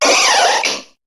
Cri de Colossinge dans Pokémon HOME.